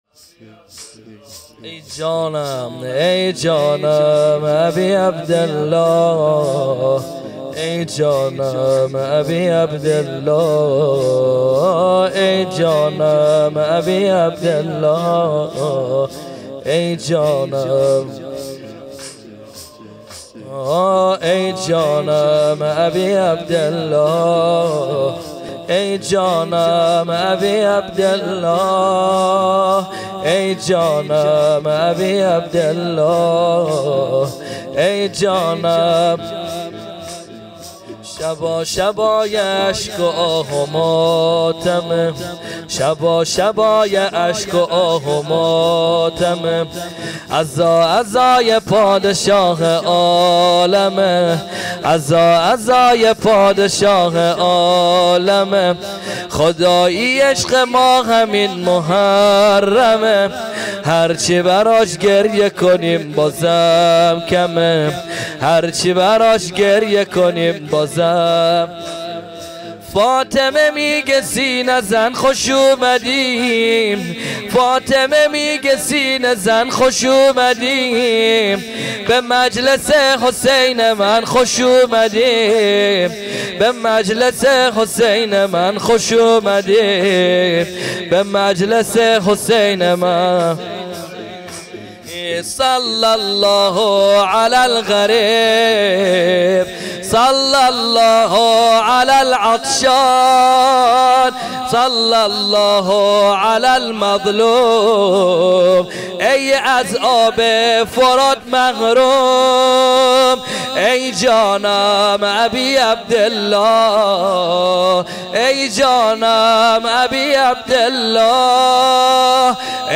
زمینه | شبا شبای اشک و آه و ماتمِ، عزا عزای پادشاهه عالمه
مداحی
شب ششم محرم الحرام 1442 ه.ق | هیأت علی اکبر بحرین